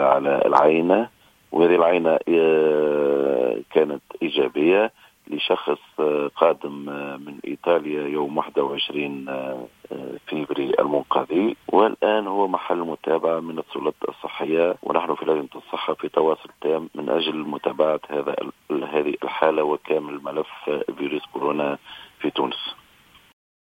أكد رئيس لجنة الصحة بالبرلمان خالد الكريشي في تصريح للجوهرة "اف ام" منذ قليل أنه تم تسجيل اصابة ثانية مؤكدة بفيروس كورونا في تونس و حاملها شخص عائد من إيطاليا منذ 21 فيفري و يبلغ من العمر 65 عاما.